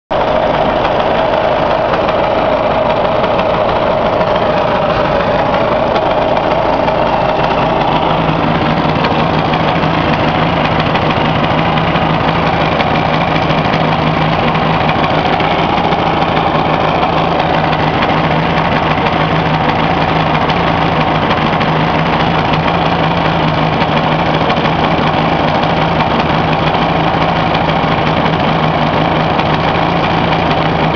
A 1500 tr/mn on dirait que ça cogne la dedans mais j'ai pas d'expérience des 2 temps alors pour juger c'est pas facile.
Ici 25 secondes de ralenti en tournant autour du bas moteur avec le micro:
Je peux me tromper, mais on dirait que ça vient des roulements de vilbrequin...